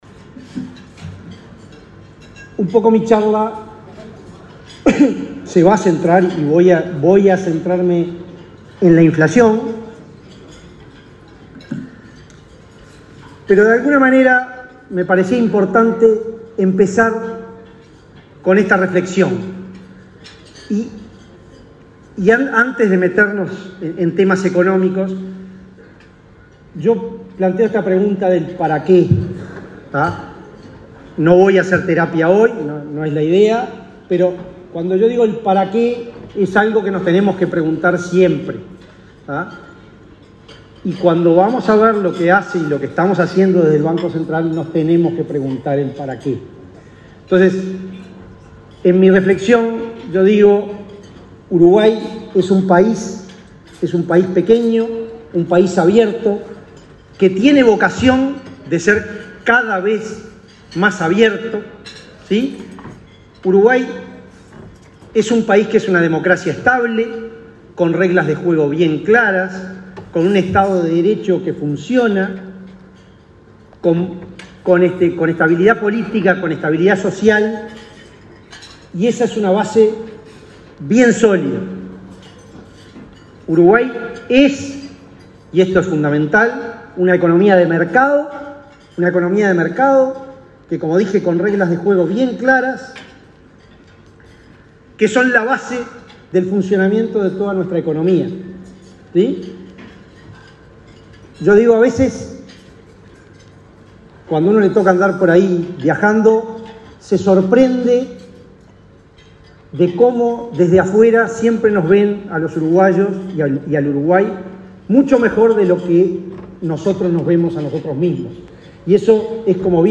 Disertación del presidente del BCU, Diego Labat
El presidente del Banco Central del Uruguay (BCU), Diego Labat, disertó este jueves 31, en un almuerzo de trabajo de la Asociación de Dirigentes de